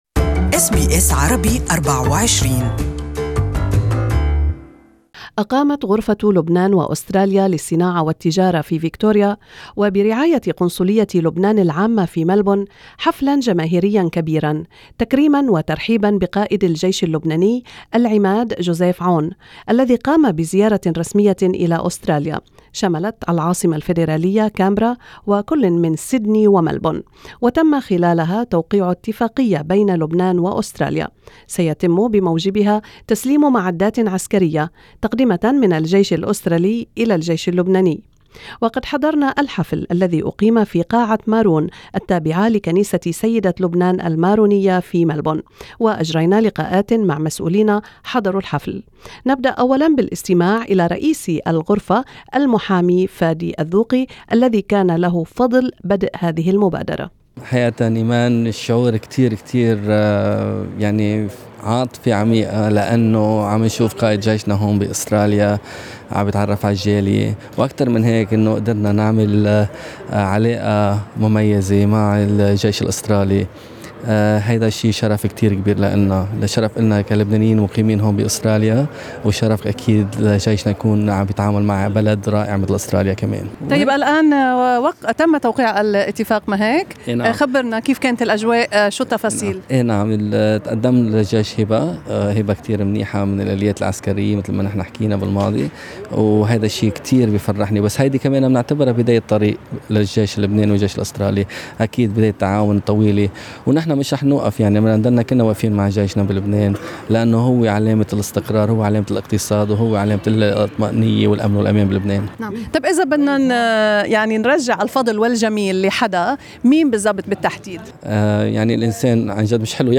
Report on the historical visit of the Commander in Chief of the Lebanese Army, General Joseph Aoun to Melbourne Australia 24 - 26 May 2018 and the dinner hosted by the Australia Lebanon Chamber of Commerce and Industry.